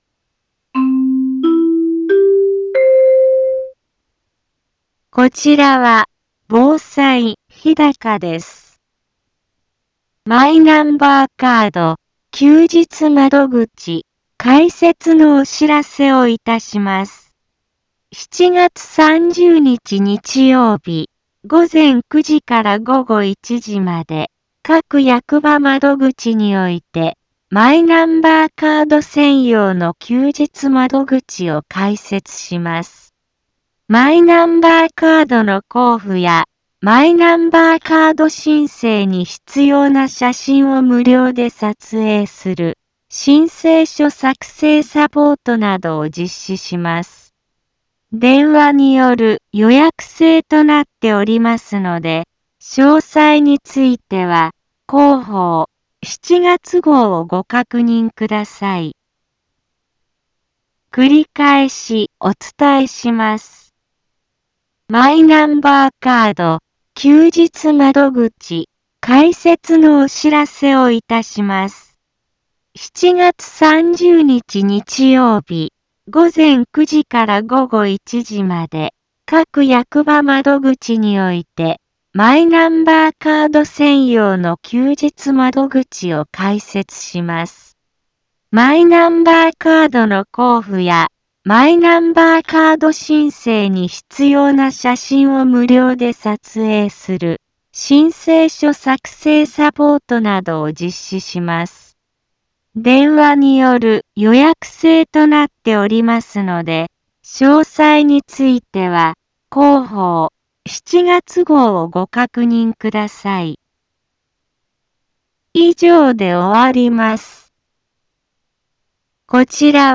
一般放送情報
Back Home 一般放送情報 音声放送 再生 一般放送情報 登録日時：2023-07-24 10:04:30 タイトル：マイナンバーカード休日窓口開設のお知らせ インフォメーション： マイナンバーカード休日窓口開設のお知らせをいたします。 7月30日日曜日、午前9時から午後1時まで、各役場窓口において、マイナンバーカード専用の休日窓口を開設します。